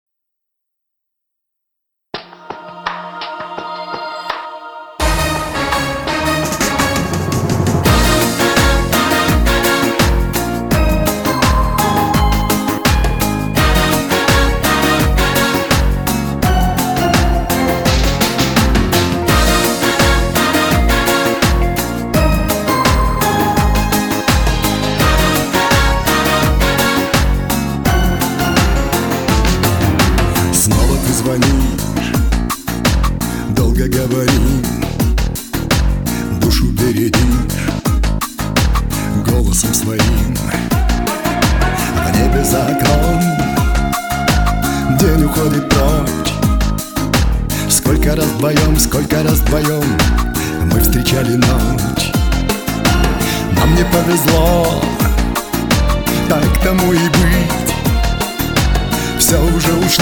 Pop
Этакая добротная советская эстрада времен Раймонда Паулса.